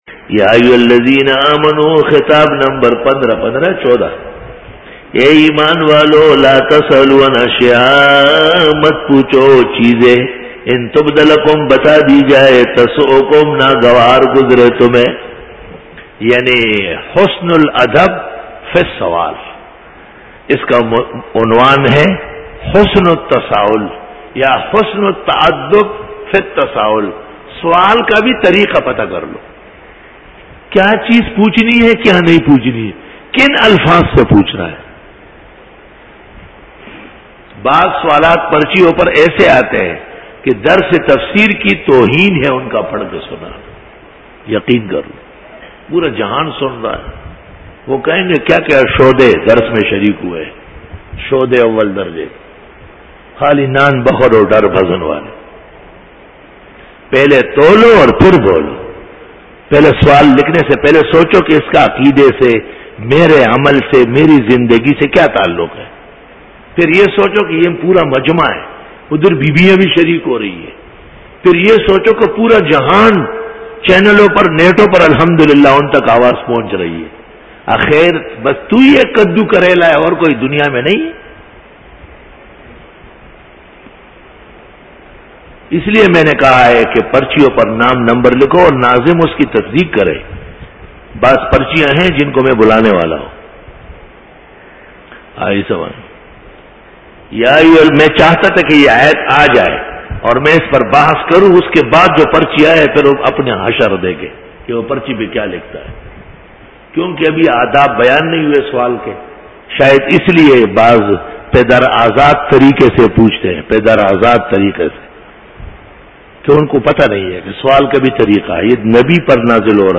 Dora-e-Tafseer 2009